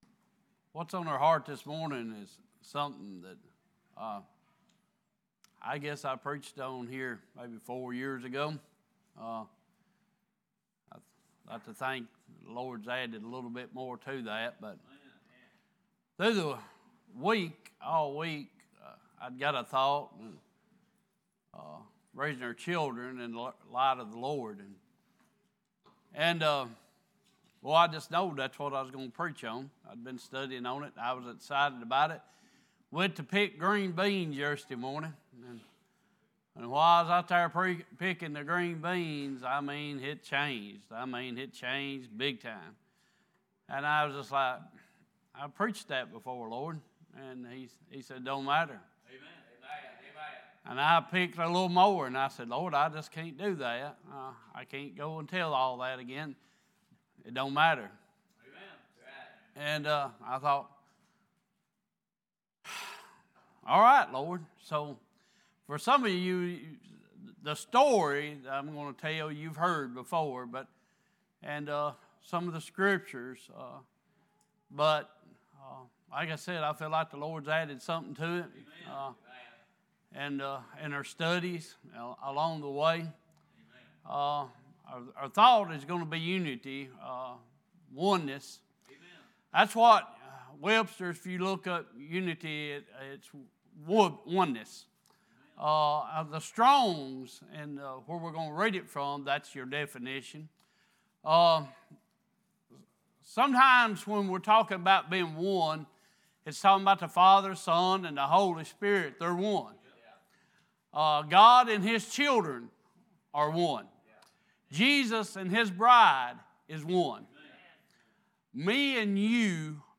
Sunday Morning Passage: Ephesians 4:1-6, Psalms 133:1-3, Ecclesiastes 4:9-12 Service Type: Worship « A Better Home Is Waiting…Don’t Miss It!